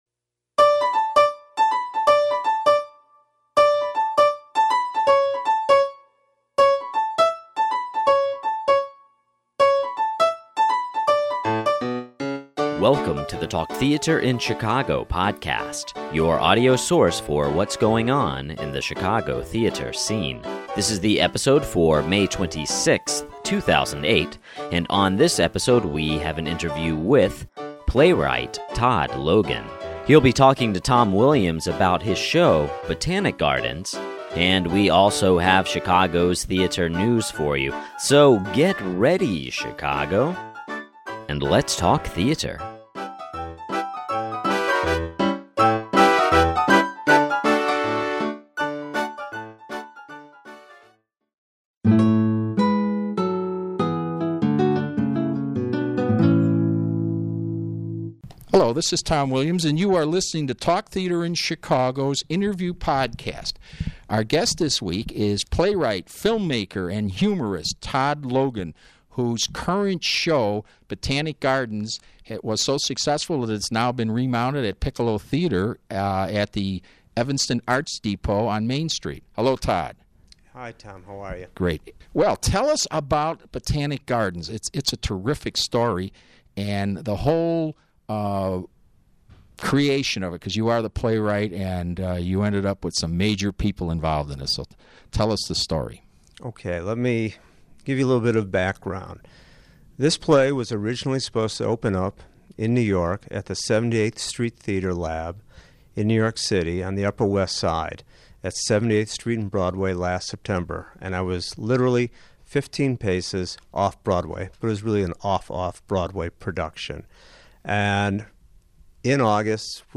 Interview Podcast
An interview with playwright